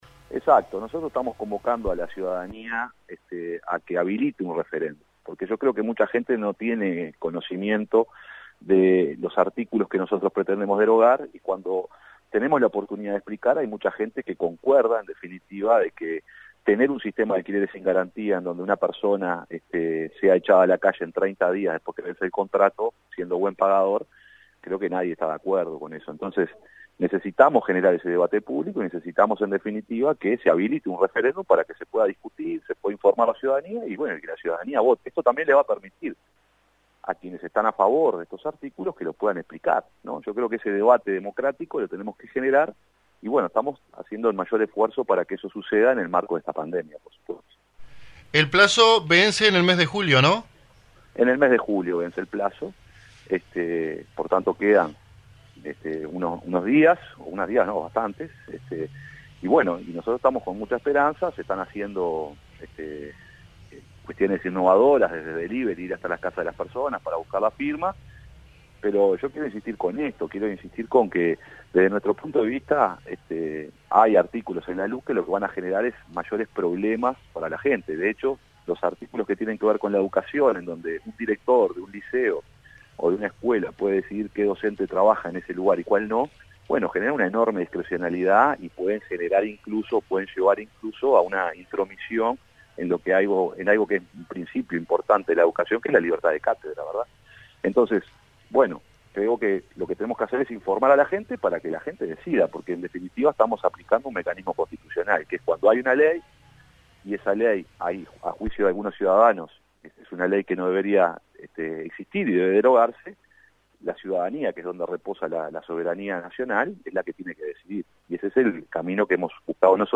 En el marco de la campaña de recolección de firmas pro referéndum que busca derogar 135 artículos de la Ley de Urgente Consideración, estuvo en dialogo con AM 1110 el Senador de la República por el FA, Alejandro Pacha Sánchez brindando información al respecto.